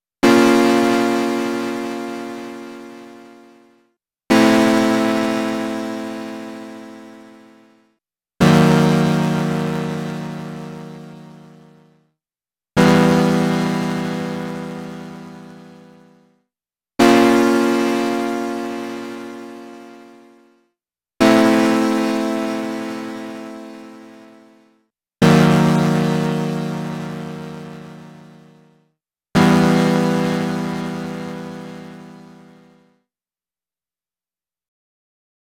Guess it largely depends on what you mean by “gritty”, but here are some (poorly played) not-pleasant patches I made this afternoon.
No external FX, played straight from the Super6 into an audio interface (TASCAM Model16, which is pretty clean).